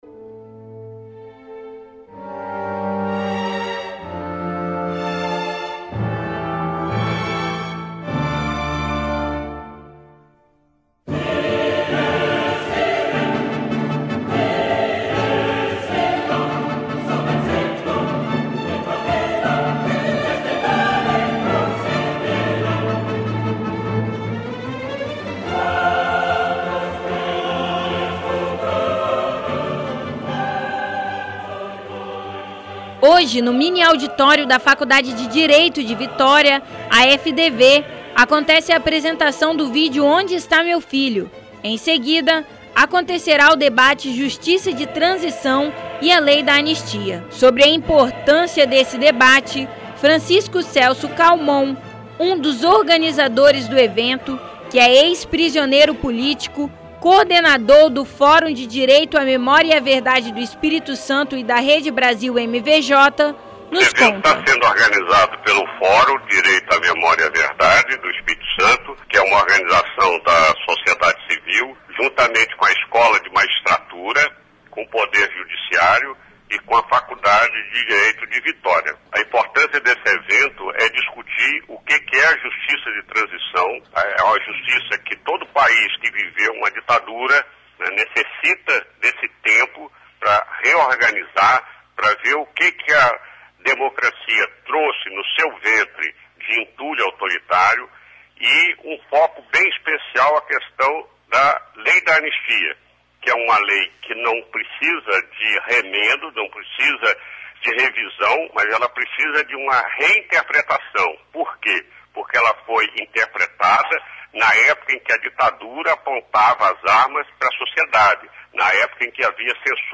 debate_anistia_fdv_.mp3